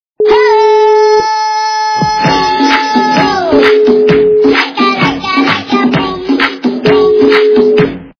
- Смешные
» Звуки » Смешные » Смешной голос напевает - индианские мотивы
При прослушивании Смешной голос напевает - индианские мотивы качество понижено и присутствуют гудки.